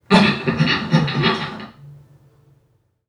NPC_Creatures_Vocalisations_Robothead [89].wav